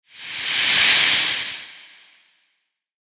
Foam Rustling Short Transition Sound Effect
Description: Foam rustling short transition sound effect. This carbonated drink foam sound captures a short, fast transition noise as fizzy bubbles rise and burst. The quick foamy hiss creates a clean and energetic audio transition.
Foam-rustling-short-transition-sound-effect.mp3